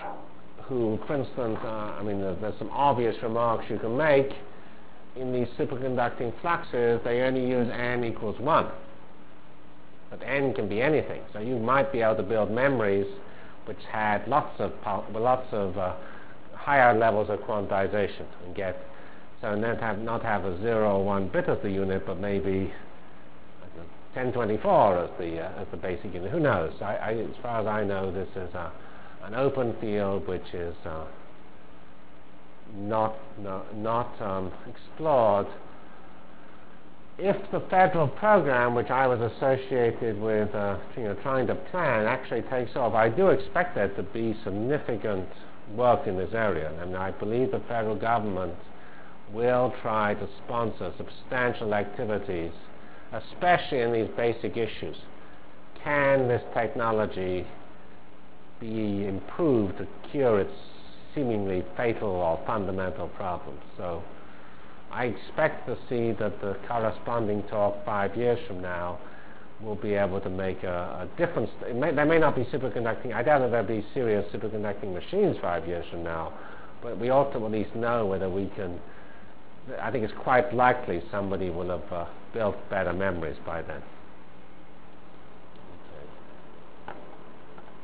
From CPS615-Lecture on Performance(end) and Computer Technologies(start) Delivered Lectures of CPS615 Basic Simulation Track for Computational Science -- 5 September 96.